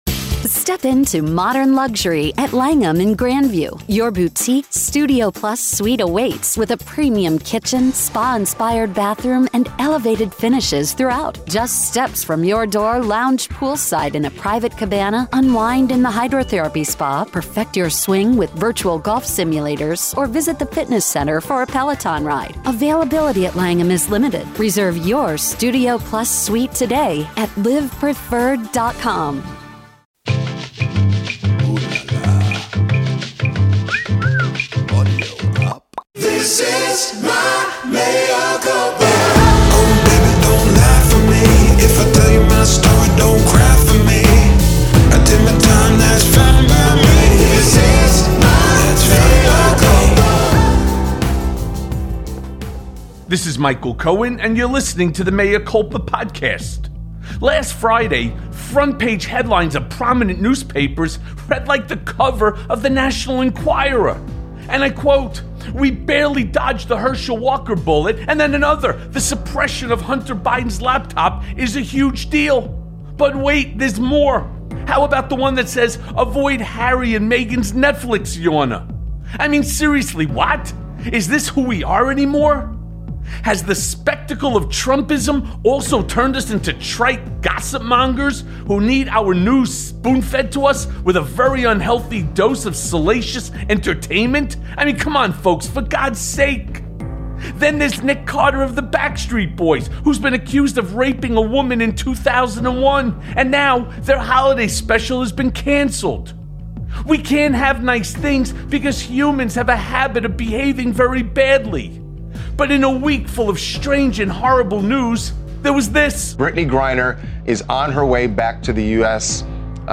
Breaking!!! Trump Will Burn Down Country if DOJ Doesn't Back Off+ A Conversation With Glenn Kirschner